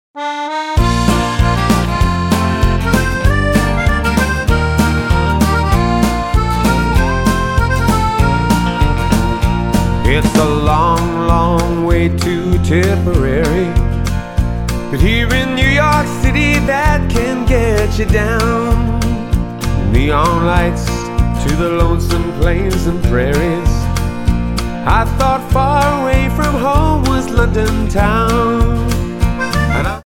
Tonart:E-F Multifile (kein Sofortdownload.
Die besten Playbacks Instrumentals und Karaoke Versionen .